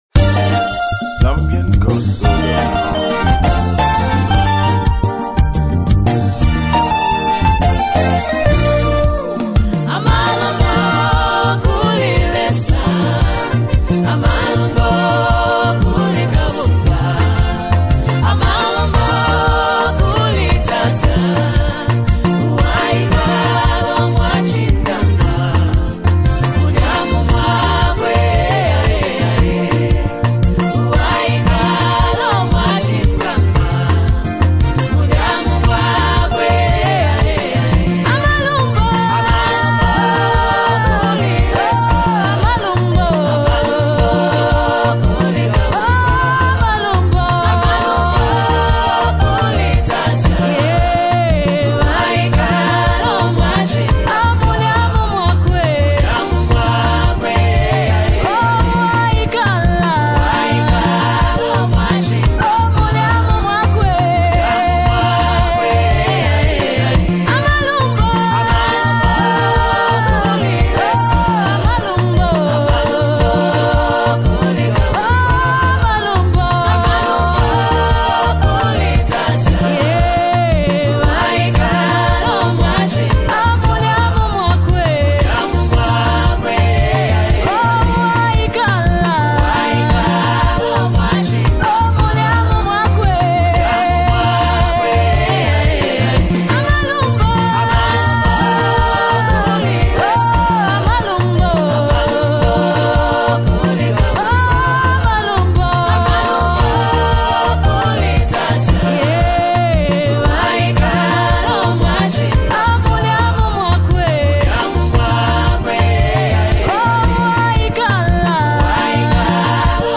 Zambian gospel artist
worship anthem